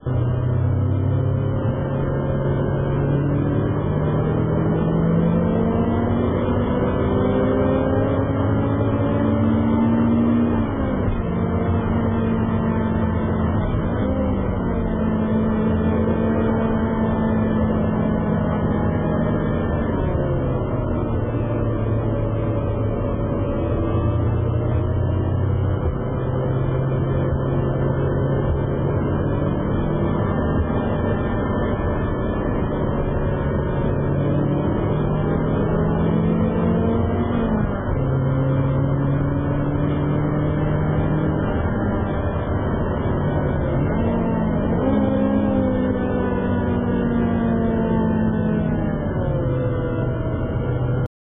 F6Bカプチーノエンジンサウンド（MP3版）（MP4再生出来ないときはコチラ）